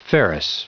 Prononciation du mot ferrous en anglais (fichier audio)
Prononciation du mot : ferrous
ferrous.wav